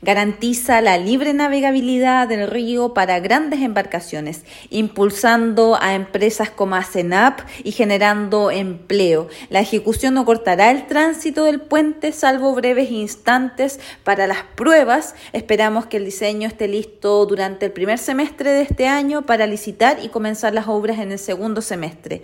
Ante ello, la seremi de Obras Públicas en Los Ríos, Nuvia Peralta, dijo que la ejecución de esta iniciativa para aumentar la apertura del puente, la proyectan para el segundo semestre.